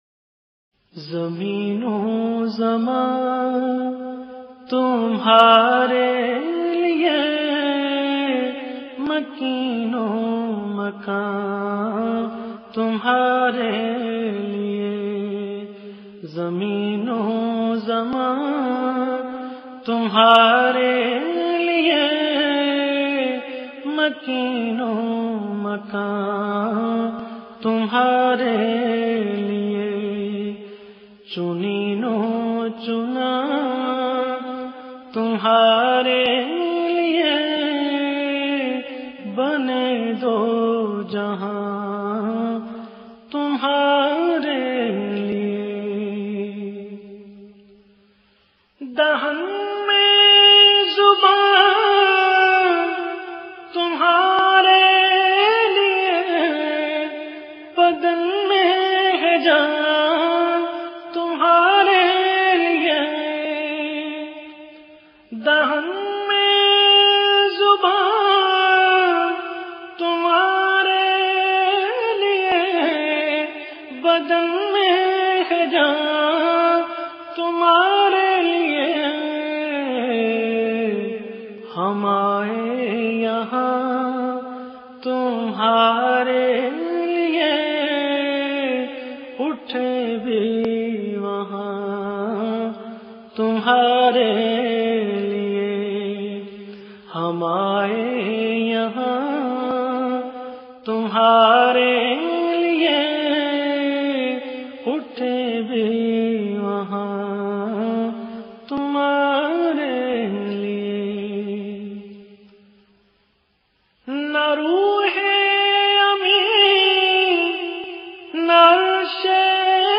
Beautiful Naat
in best audio quality
Lyrics